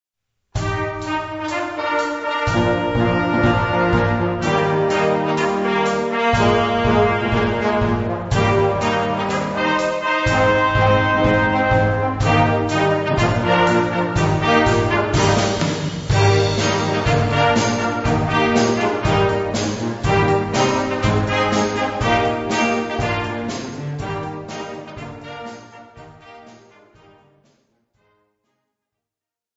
Categorie Harmonie/Fanfare/Brass-orkest
Subcategorie Concertmuziek
Instrumentatie/info 4part; Perc (slaginstrument)